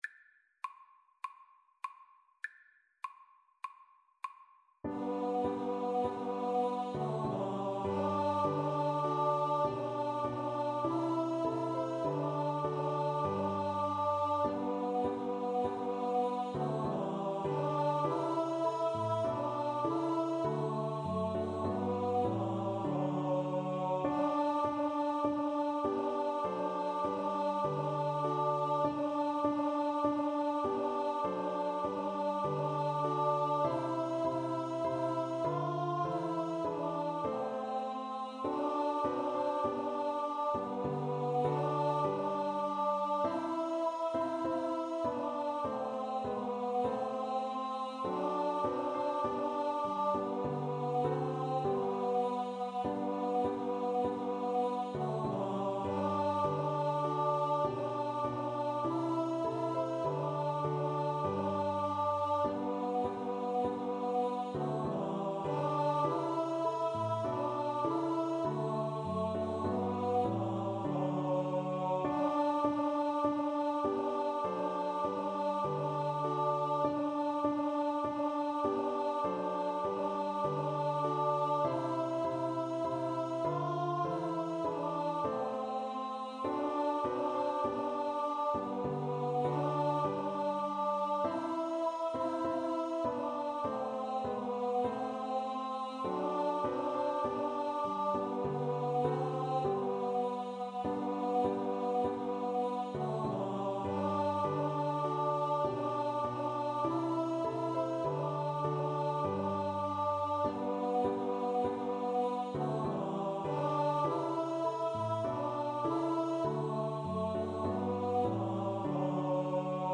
Free Sheet music for Choir (SATB)
G major (Sounding Pitch) (View more G major Music for Choir )
4/4 (View more 4/4 Music)
Traditional (View more Traditional Choir Music)
hark_the_herald_CHOIR_kar3.mp3